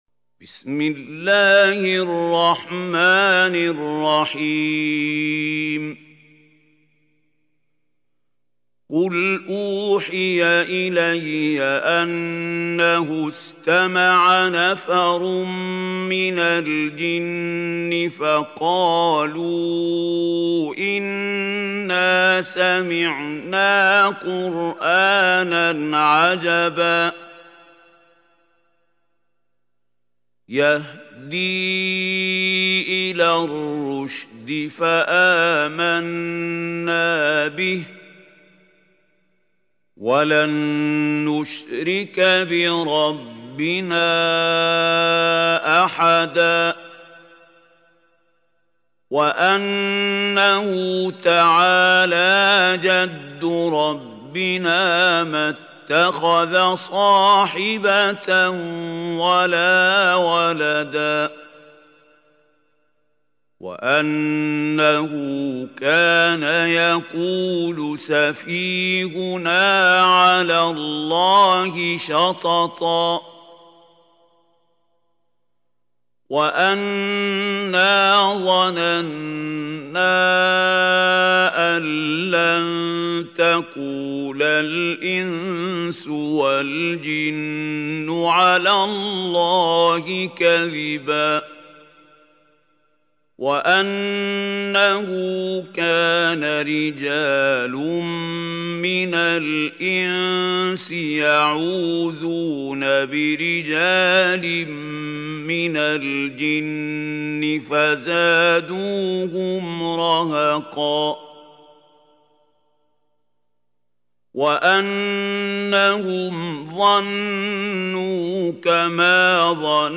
سورة الجن | القارئ محمود خليل الحصري